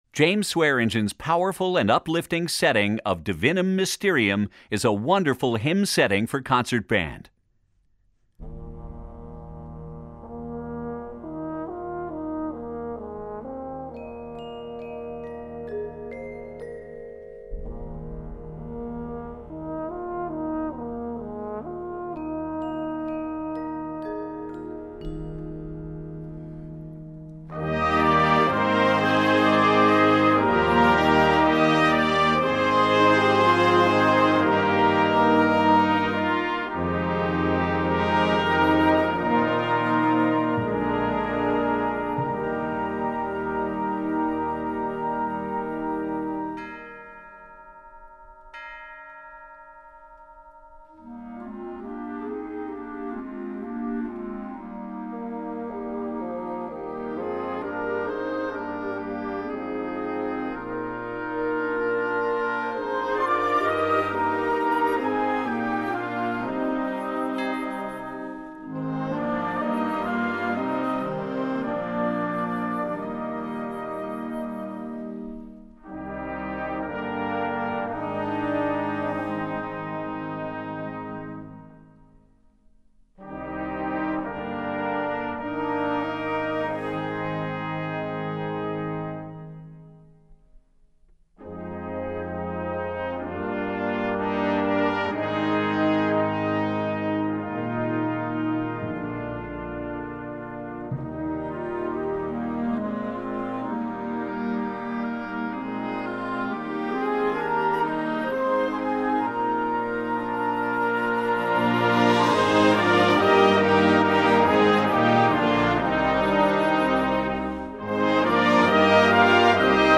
Besetzung: Blasorchester
Choralsatz